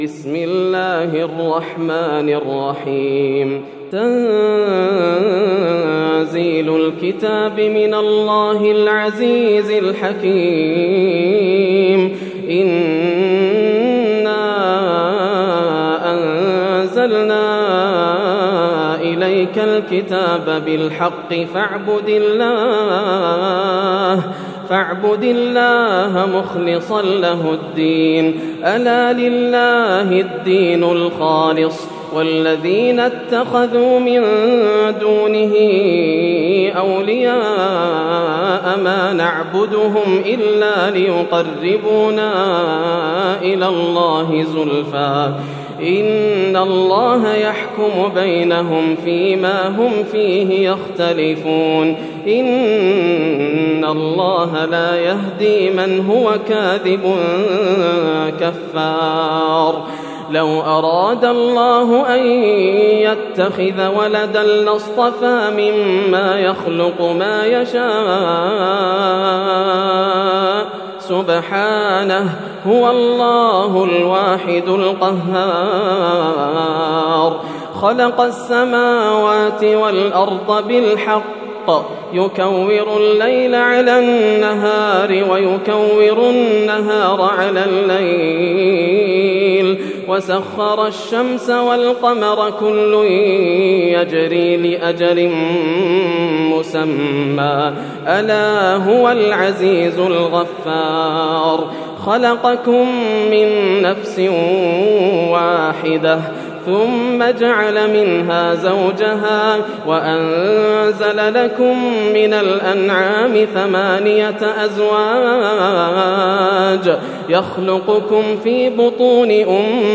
سورة الزمر > السور المكتملة > رمضان 1431هـ > التراويح - تلاوات ياسر الدوسري